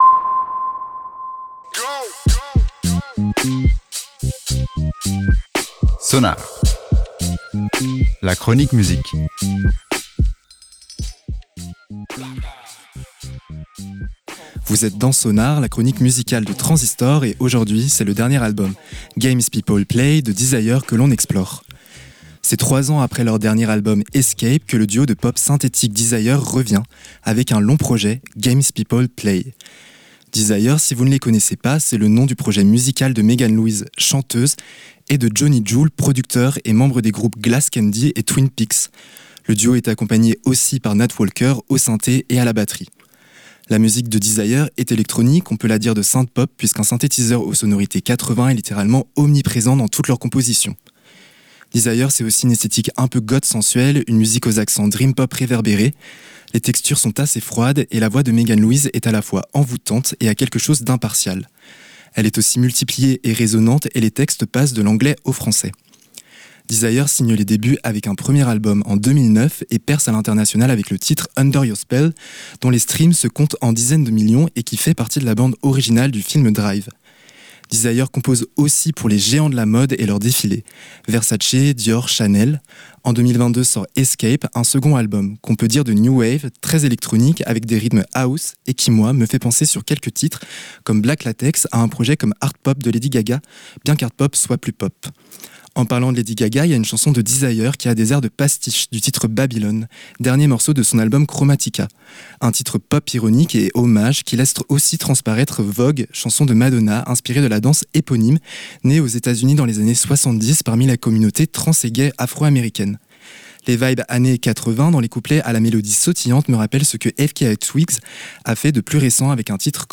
est instrumentale et immersive
est plus dansante